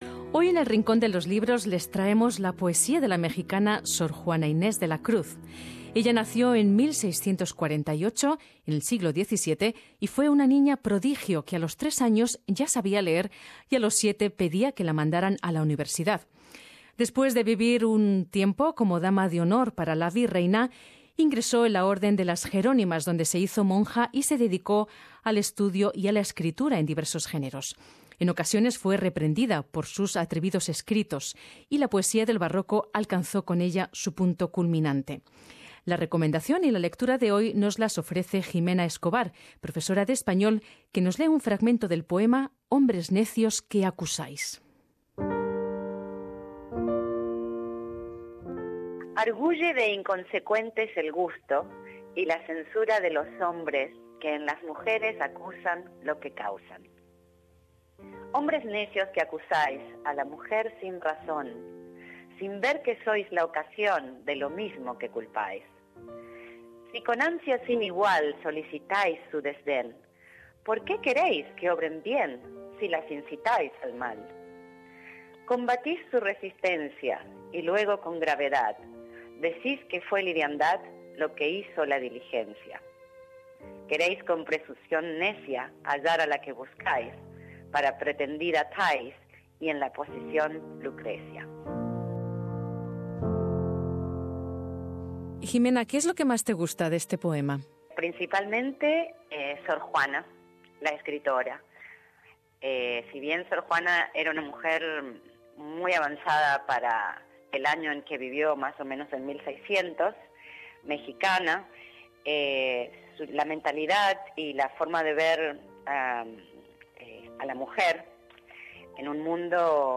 Lectura recomendada